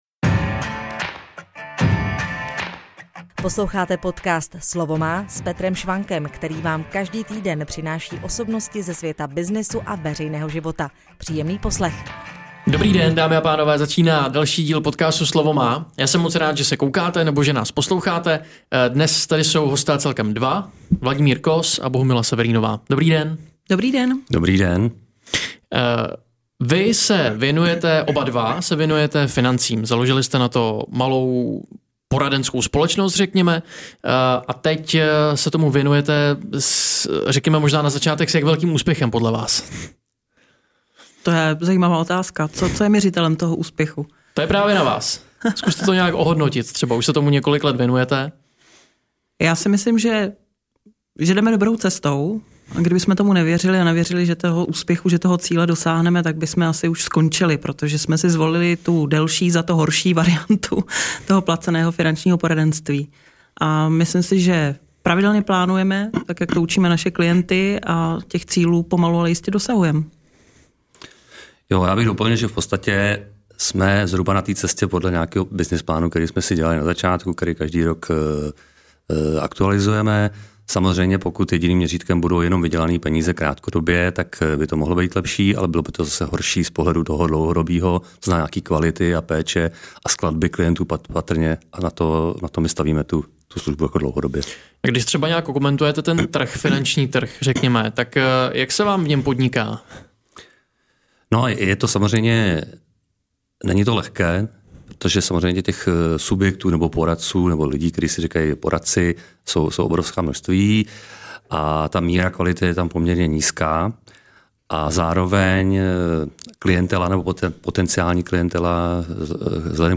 Zkušení finanční poradci vytvořili dvojku, která pod značkou Saturia pomáhá klientům se stanovením jejich cílů. V rozhovoru se tedy dozvíte především o významu dobře nastaveného finančního plánu.